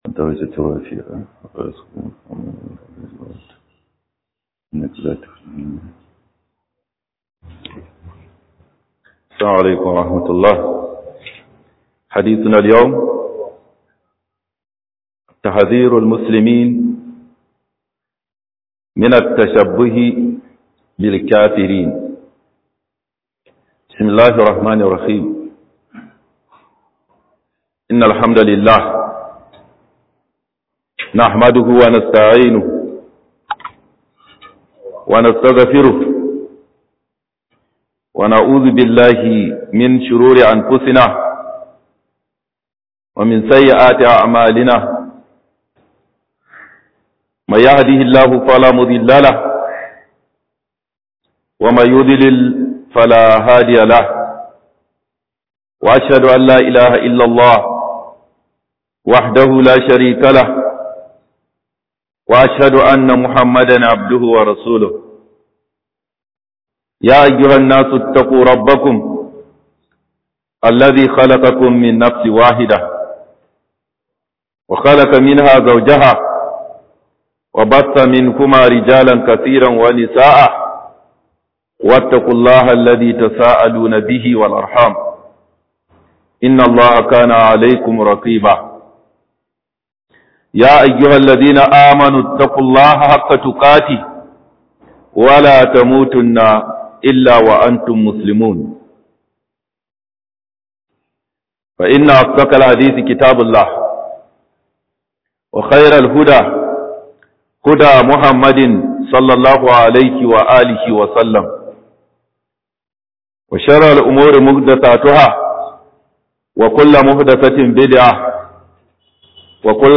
HUƊUBAR JUMAA MAUDUI TSORATAR DA MUSULMAI KAMAN CECENIYA DA KAFIRAI